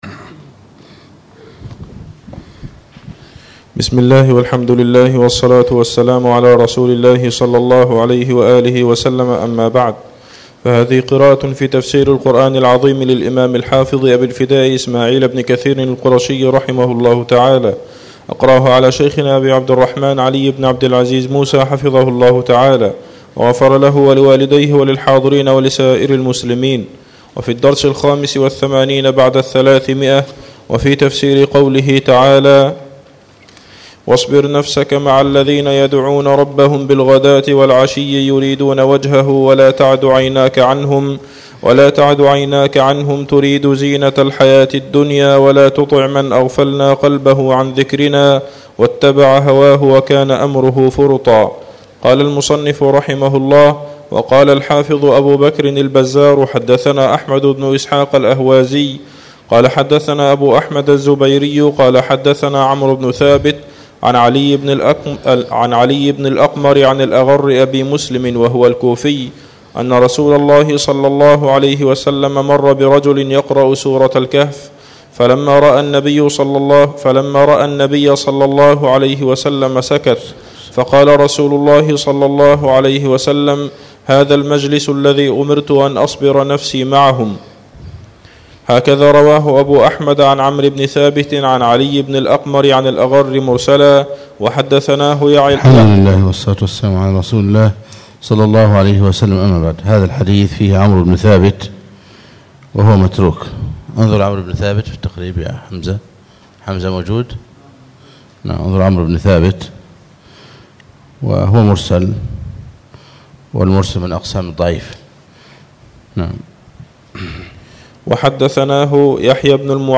الدرس الخامس والثمانون بعد الثلاثمئه